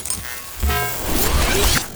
BSword1.wav